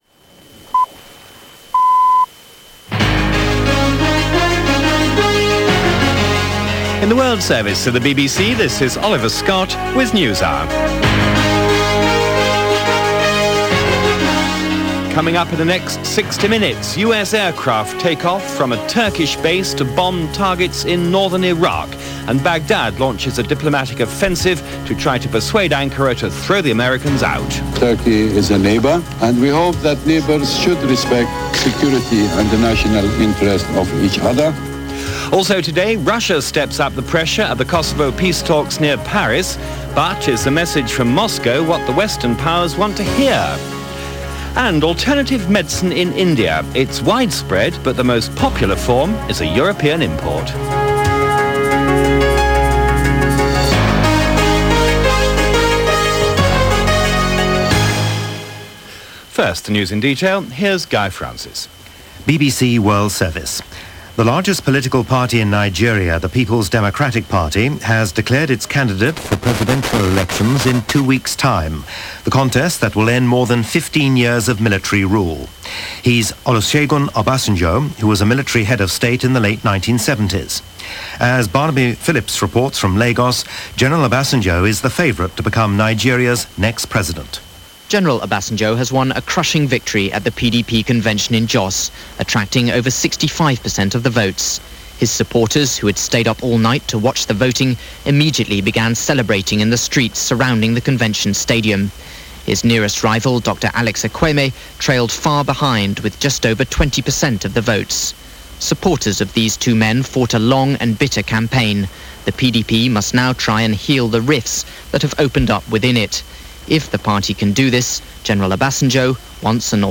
From the BBC World Service, a look at what was happening – and there was much.